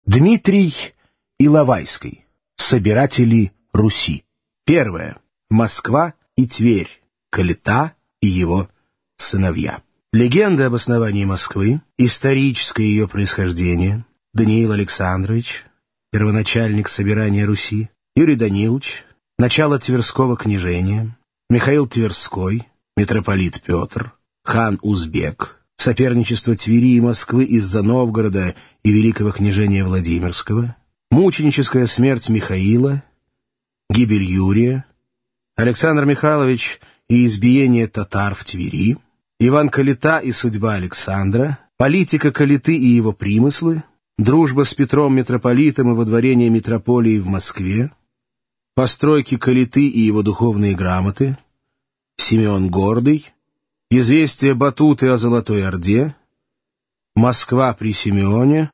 Аудиокнига Собиратели Руси | Библиотека аудиокниг